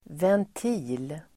Uttal: [vent'i:l]